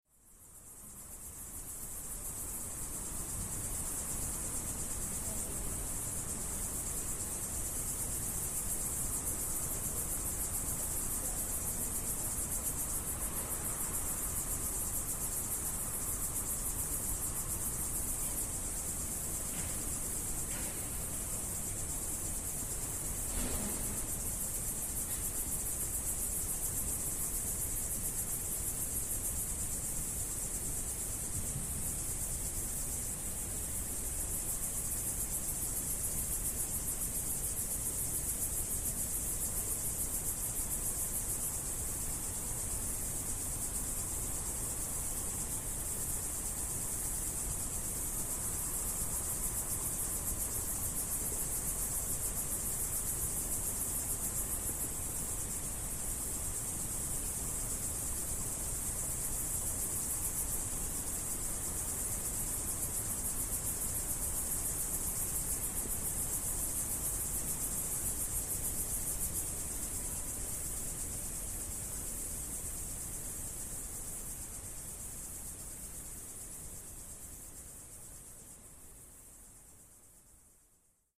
Mezzanotte in montagna.mp3